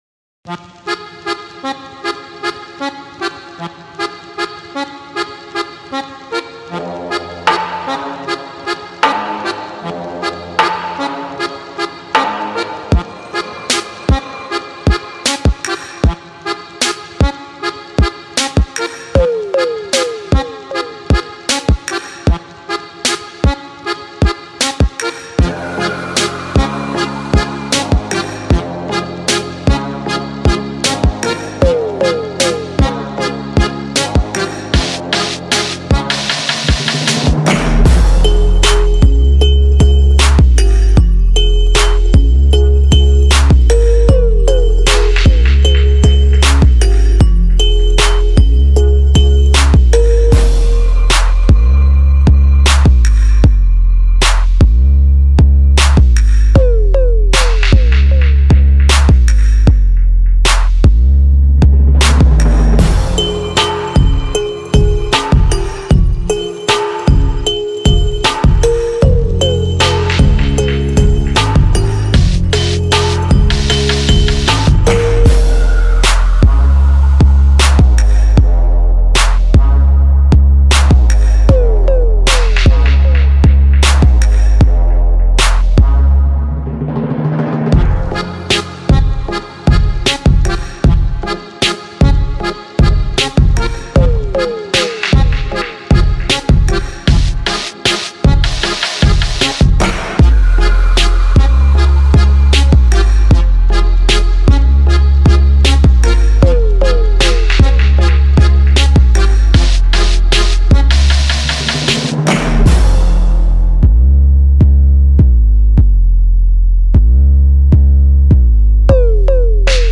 Instrumentais